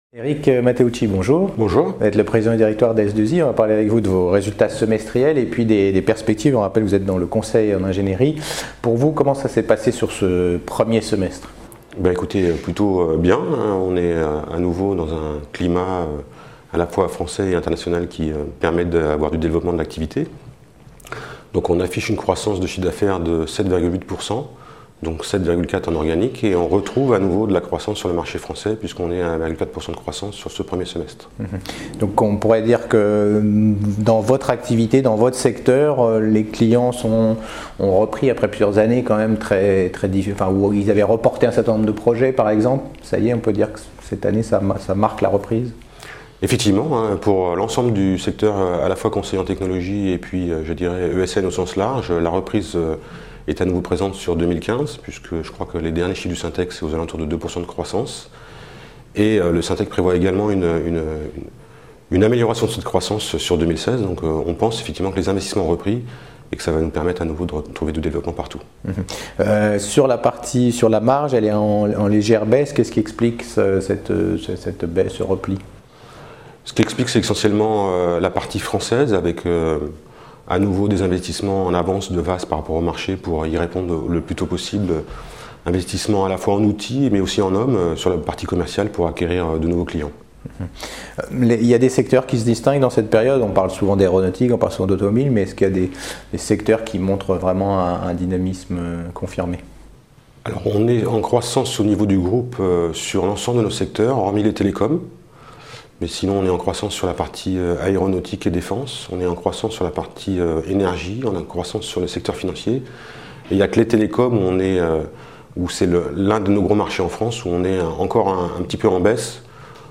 Nous parlons de la stratégie de l’entreprise, des acquisitions, et des perspectives sur différents secteurs avec mon invité